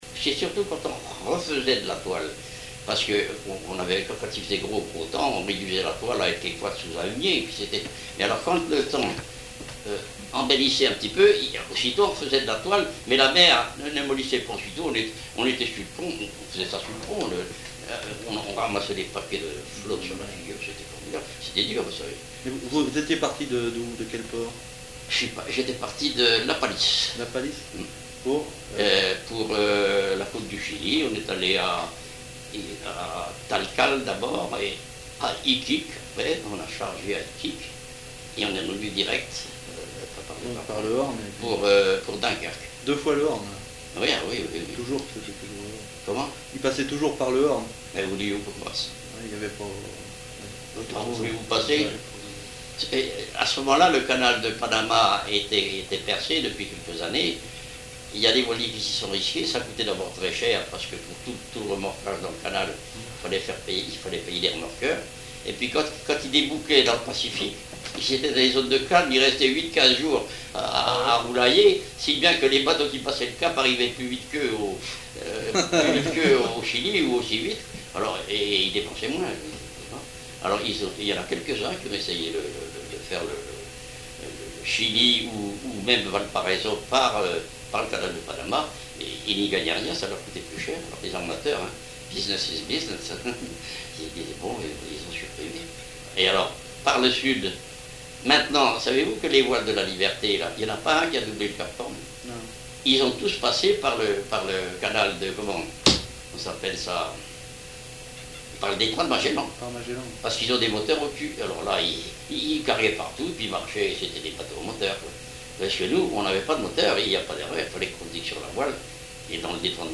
Témoignage d'un cap-hornier
Catégorie Témoignage